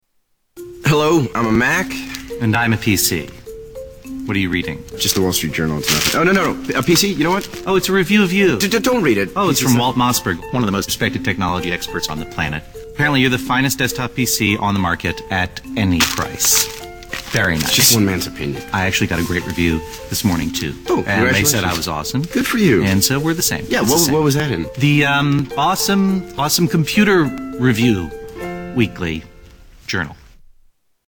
Tags: Media Apple Mac Guy Vs. PC Guy Commercial Justin Long John Hodgeman